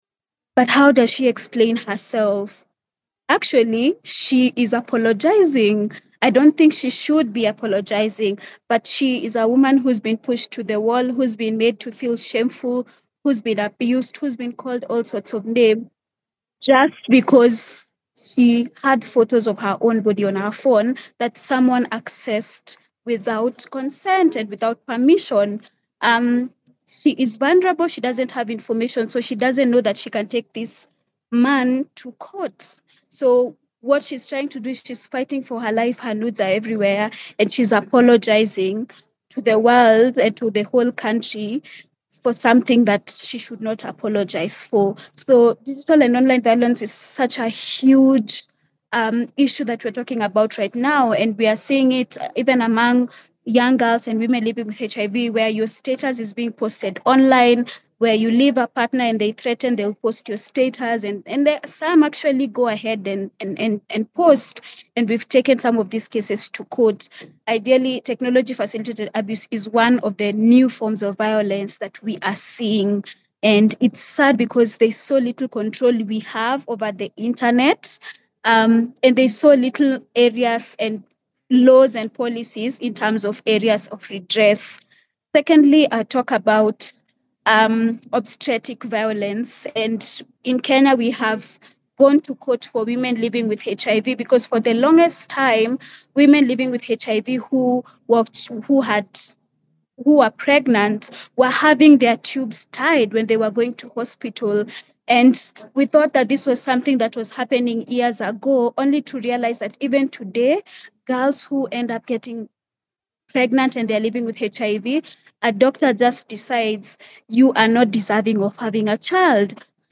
Webinar: Shifts in Ending New and Evolving Forms of Violence Part 4 – International Community of Women living with HIV Eastern Africa